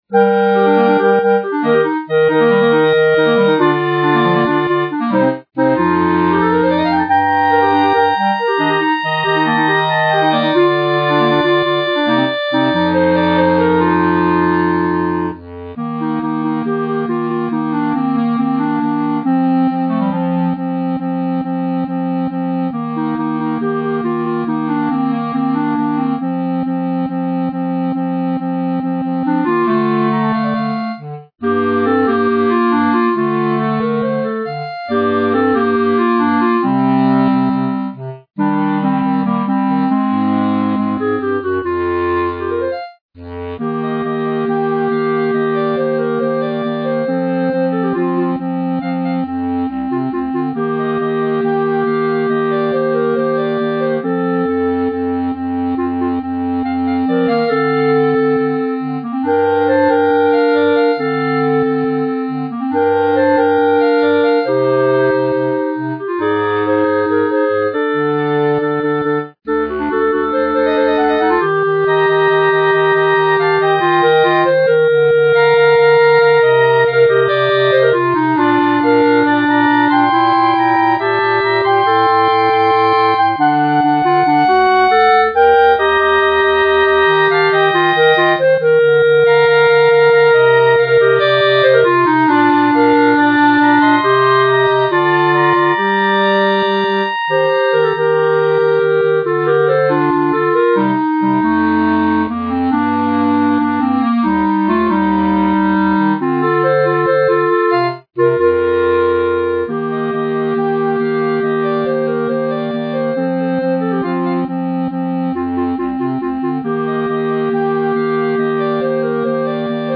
B♭ Clarinet 1 B♭ Clarinet 2 B♭ Clarinet 3 Bass Clarinet
单簧管四重奏
这是一首经典的J-POP圣诞歌曲。